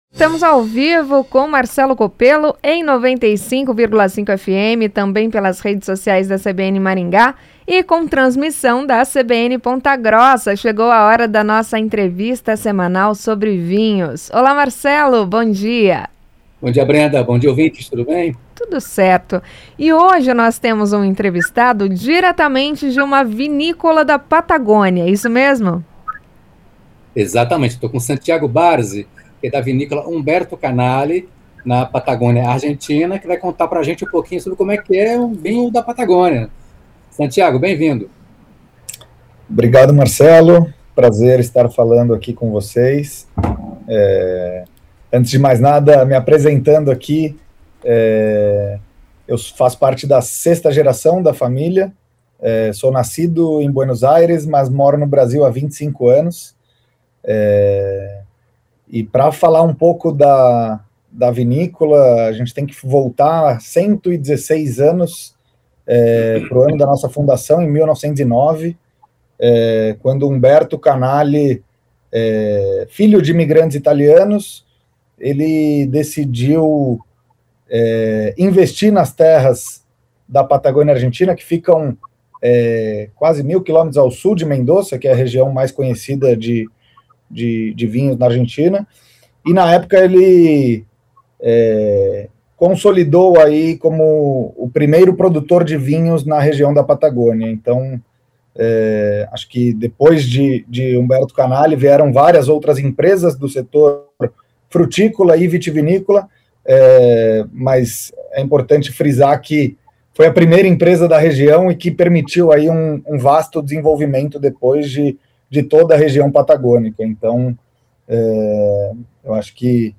Produtor explica o que diferencia os vinhos da Patagônia